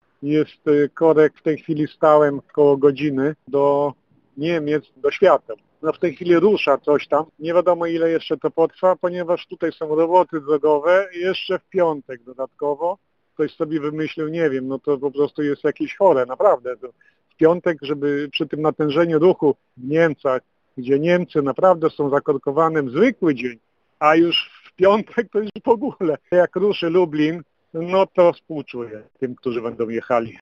Ogromny korek na drodze krajowej nr 19 od Lublina do Lubartowa – alarmuje słuchacz Radia Lublin.